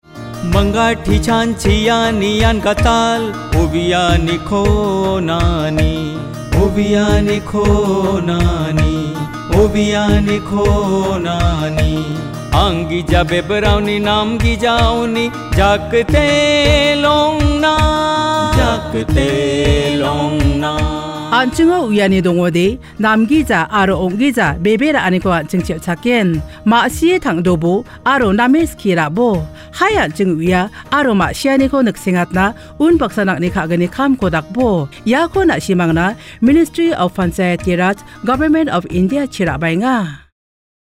54 Fundamental Duty 8th Fundamental Duty Develop scientific temper Radio Jingle Garo